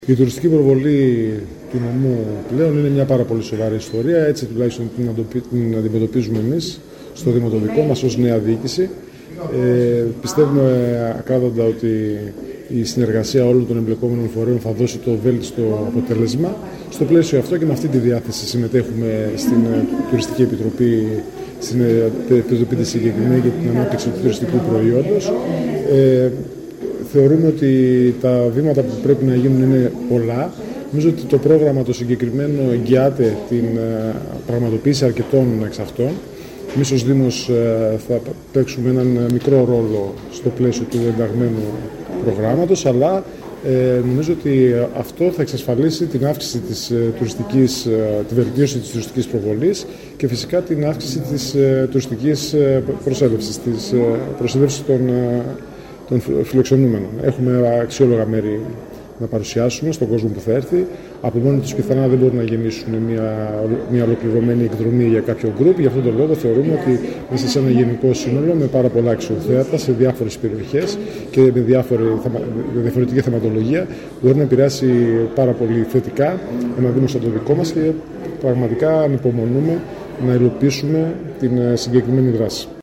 Βογιατζής Γιώργος – Δήμαρχος Δοξάτου